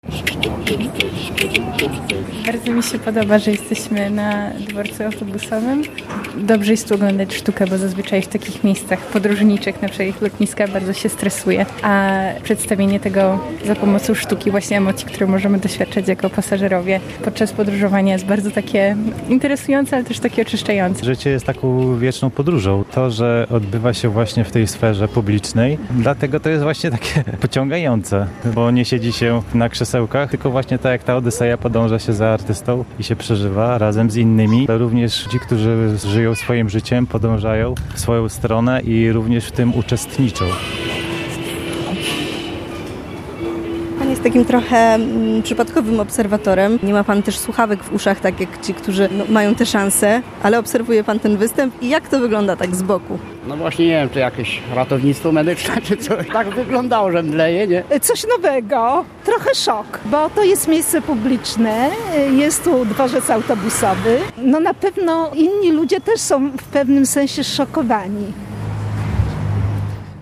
Jednym z nich był spektakl „Odyseja” odegrany w wyjątkowej scenerii dworca autobusowego w Rzeszowie.